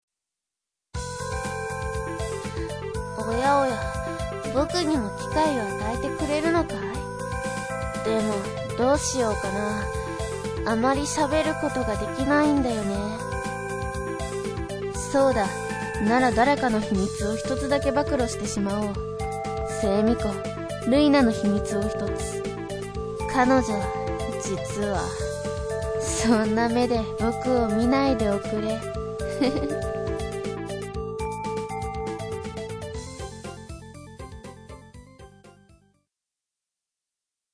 かなり高飛車な喋り方をし、時には敵、時には味方に。
自己紹介ボイス"